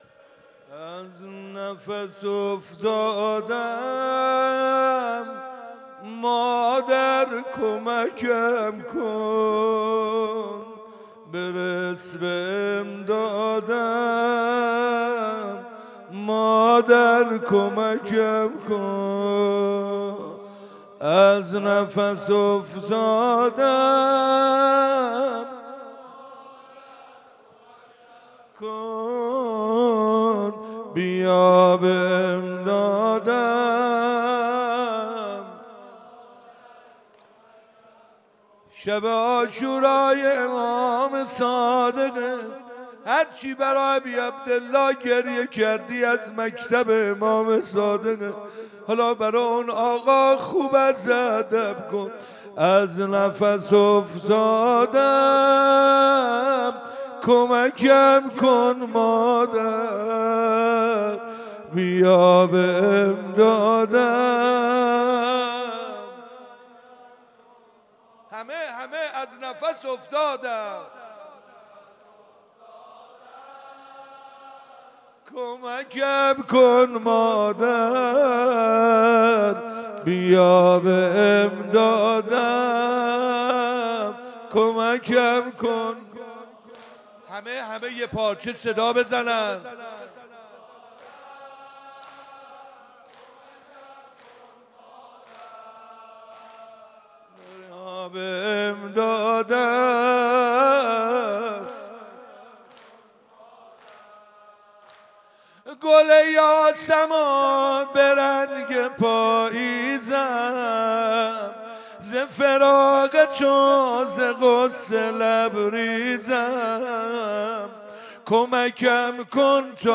17 تیر 97 - مسجد ارک - روضه - از نفس افتادم مادر کمکم کن